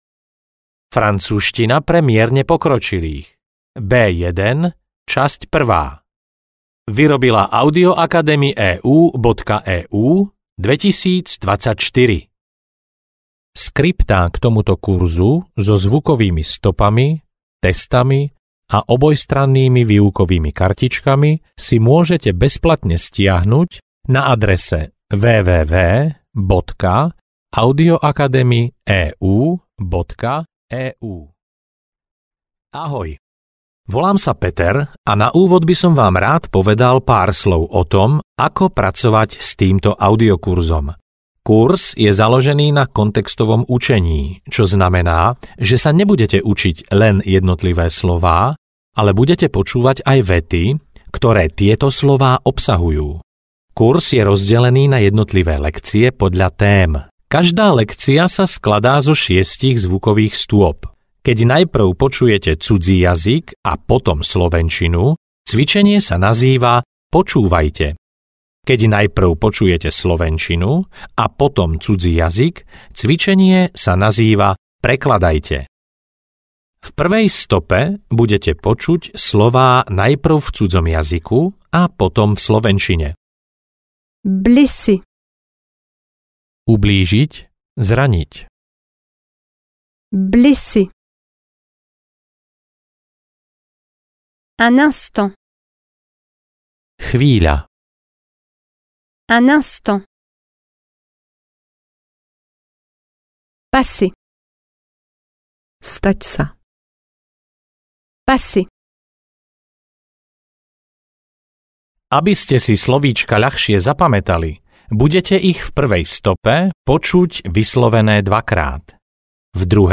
Audiokniha Francúzština pre mierne pokročilých B1 - časť 1. Jednotlivé lekcie sú rozdelené podľa tém a každá obsahuje iný počet slov a viet.
Ukázka z knihy